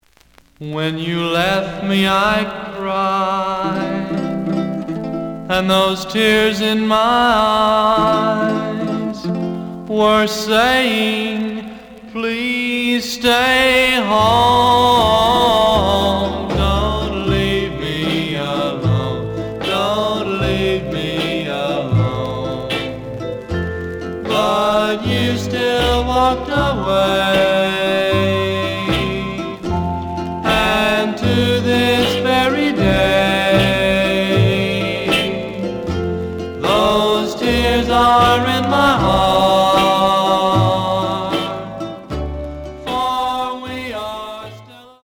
The audio sample is recorded from the actual item.
●Genre: Rhythm And Blues / Rock 'n' Roll